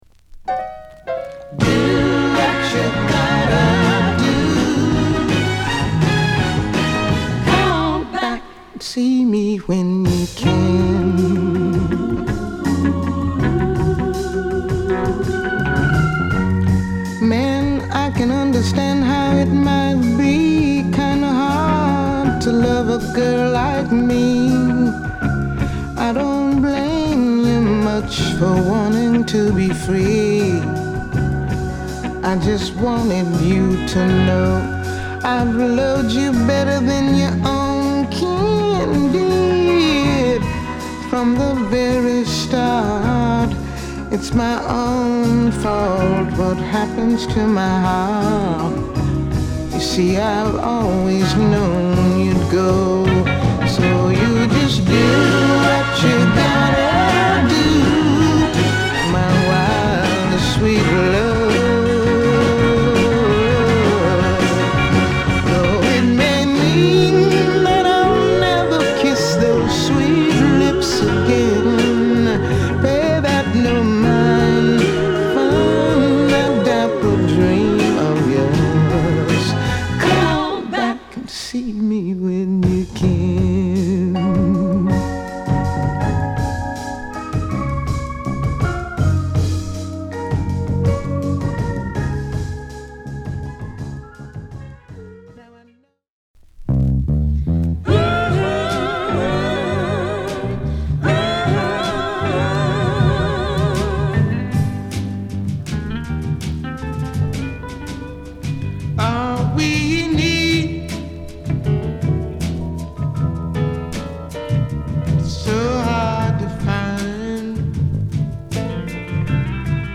重厚なボトムと郷愁のメロディが絡んだ